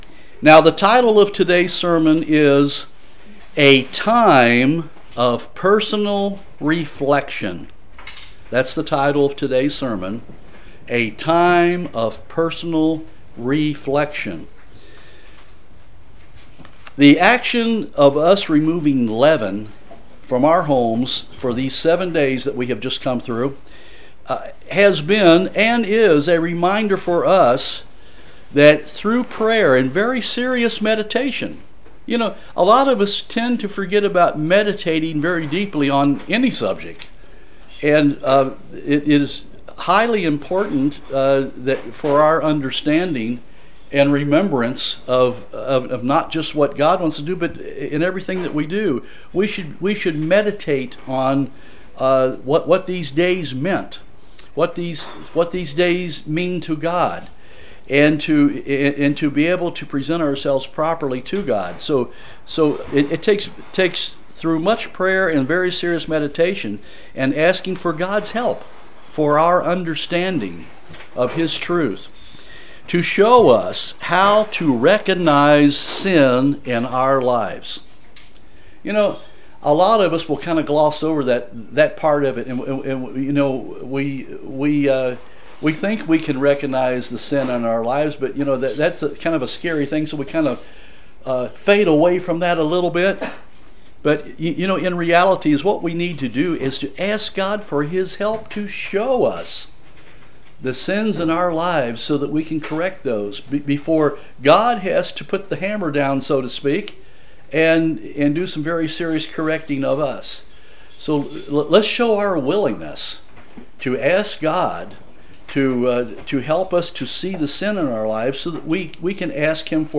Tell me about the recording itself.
Given in Jackson, TN